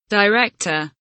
director kelimesinin anlamı, resimli anlatımı ve sesli okunuşu